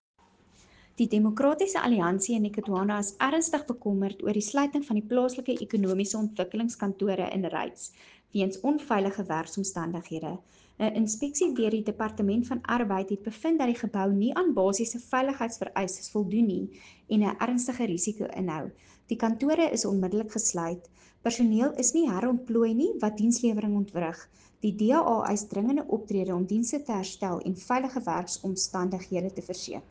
Afrikaans soundbites by Cllr Anelia Smit and